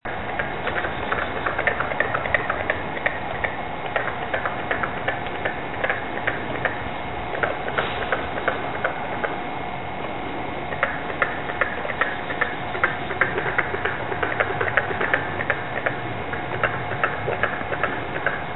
Carpenter Frog Call
Breeding occurs in late spring through summer, with males calling from the edges of wetlands.
Call: A series of sharp notes resembling the rhythmic hammering of a carpenter, giving the species its common name.